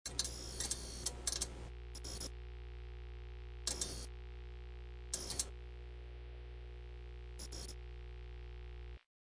Sounds of appliances
TV.mp3